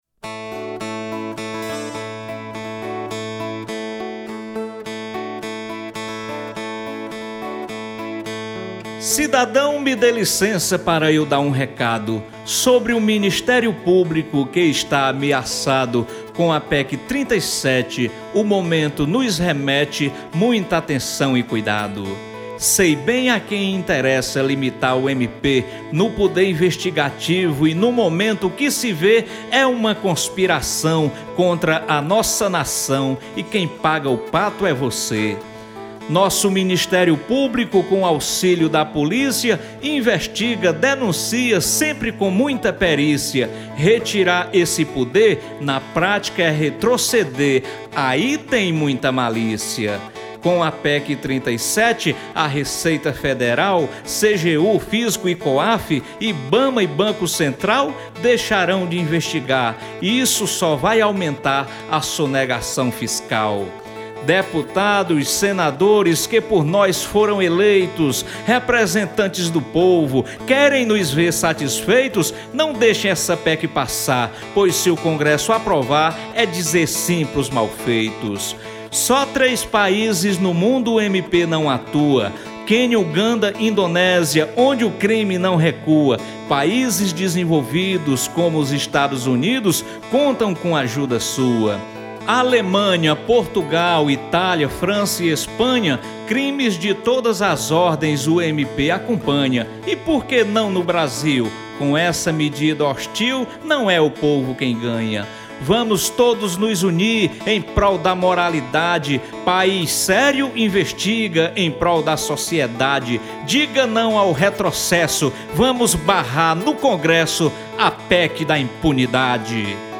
Cordel Recitado Sobre a PEC 37